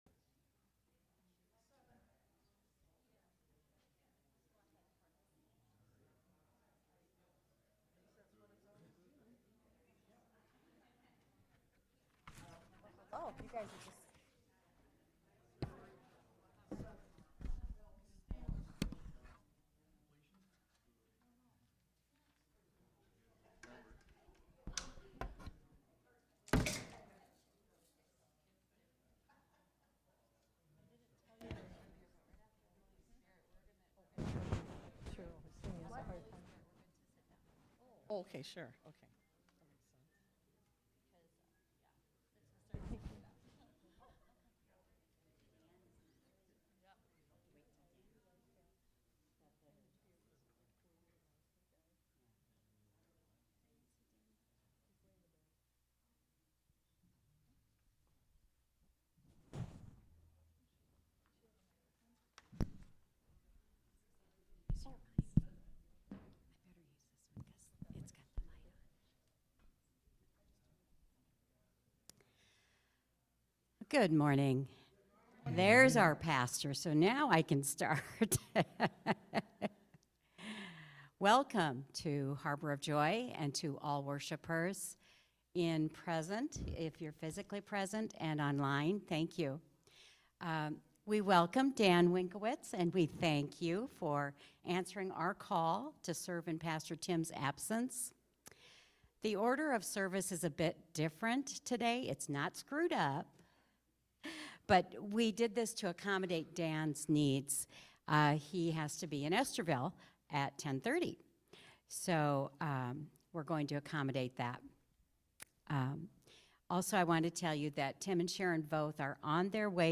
Worship-February-2-2025-Voice-Only.mp3